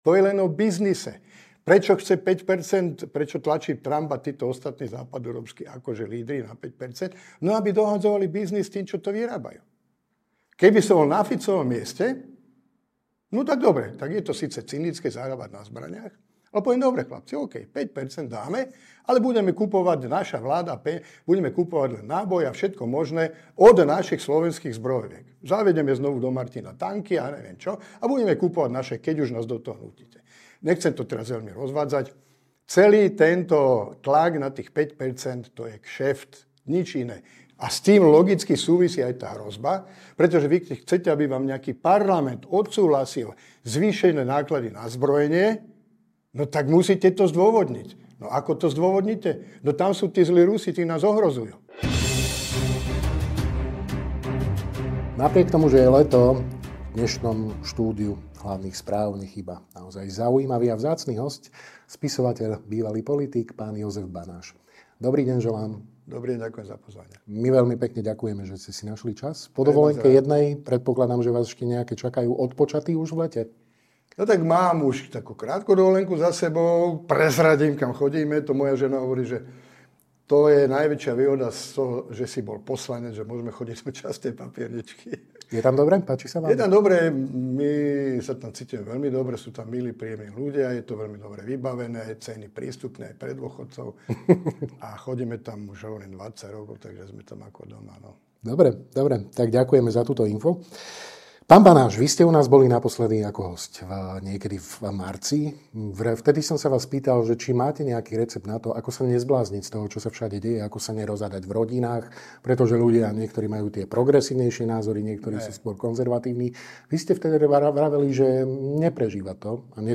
Aj to sú témy rozhovoru s Ing. Jozefom Banášom.